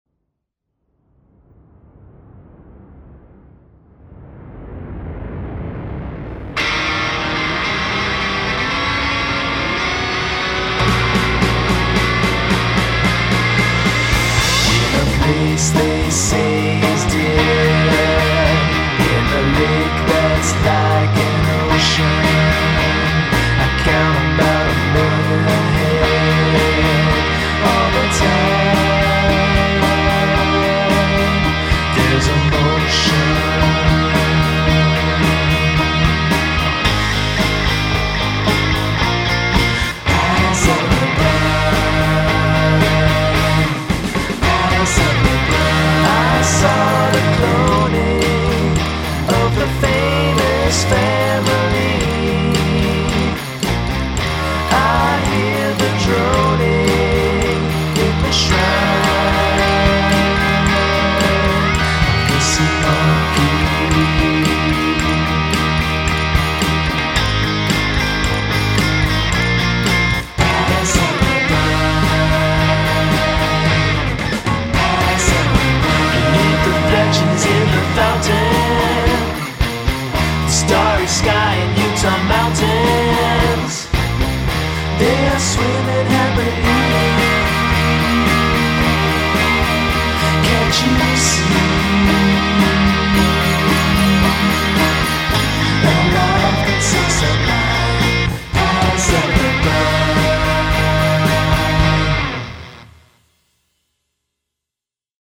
Drums
All Other Instrumentation/Vocals